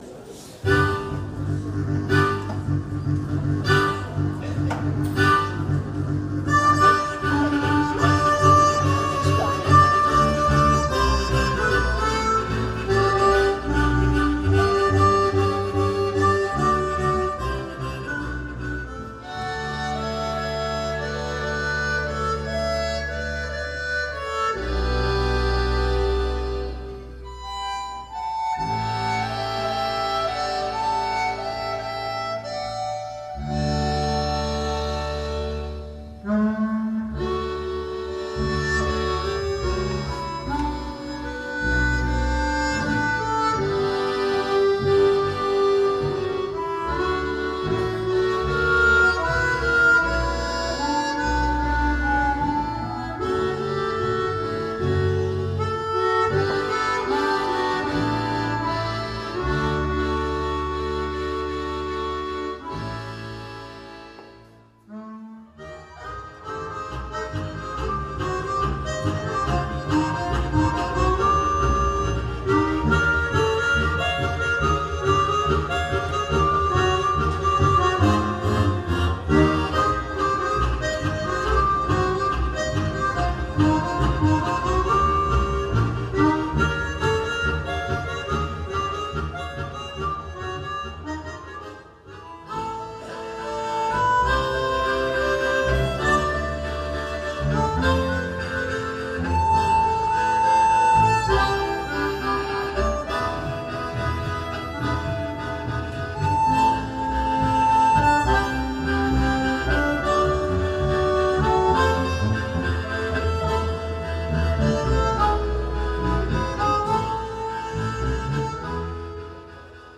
Hörprobe vom Auftritt in La Chaux-de-Fonds 2010 (MP3)